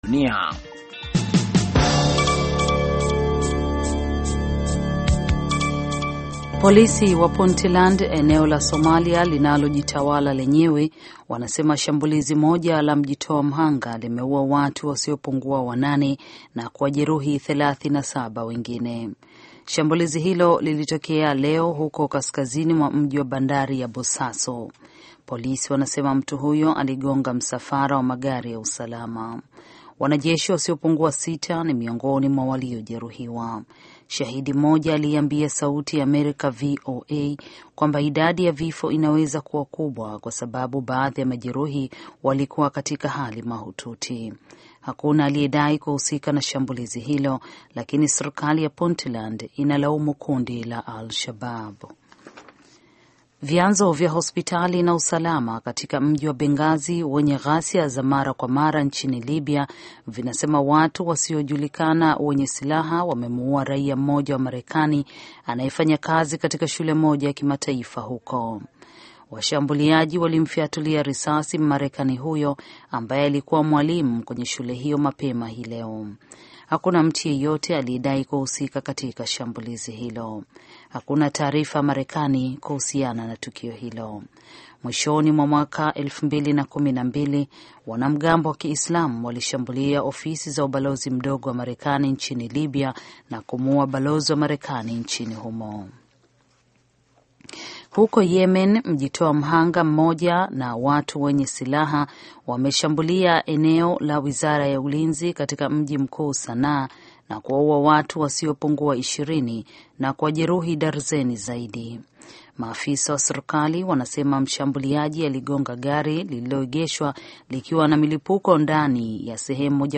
Taarifa ya Habari VOA Swahili - 6:34